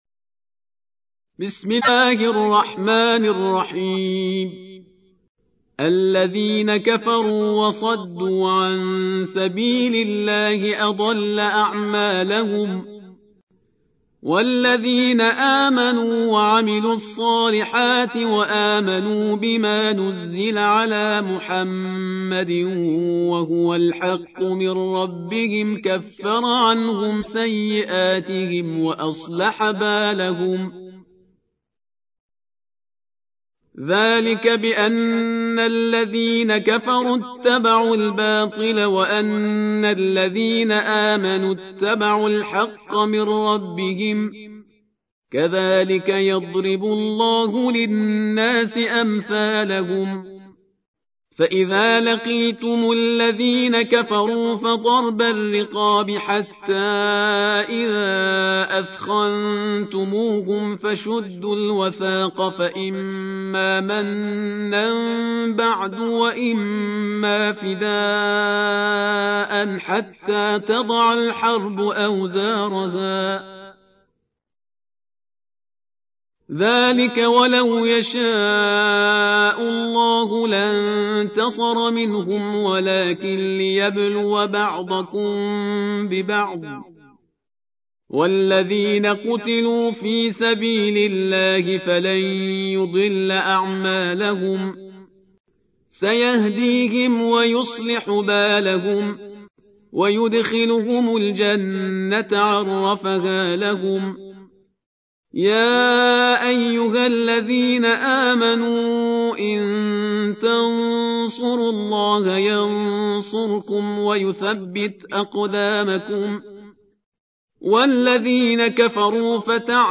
تحميل : 47. سورة محمد / القارئ شهريار برهيزكار / القرآن الكريم / موقع يا حسين